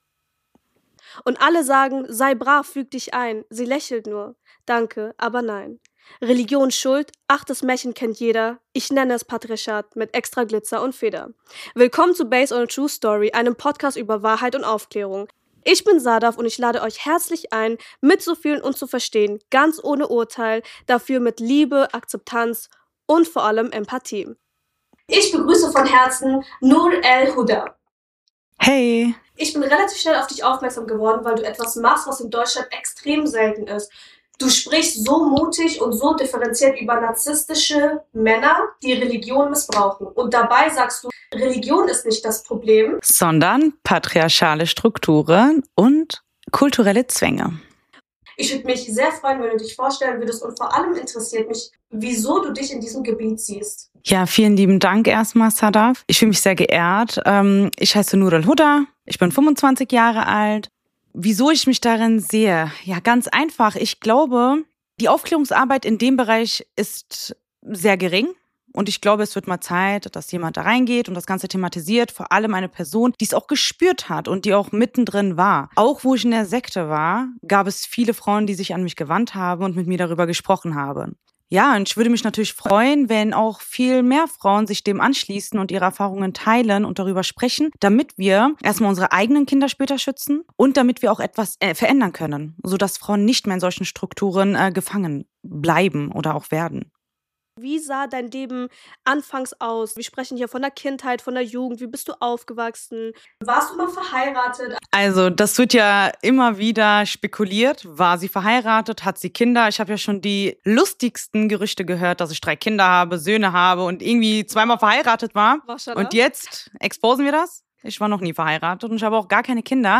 Hinweis: In den ersten zwei Minuten gibt es leider technische Probleme mit dem Mikrofon – ich entschuldige mich dafür. Danach ist die Tonqualität stabil.